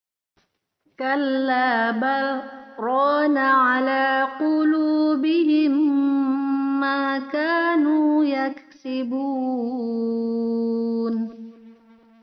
Saktah yaitu berhenti sejenak tanpa bernafas selama 2 harakat ketika membaca Al-Qur’an.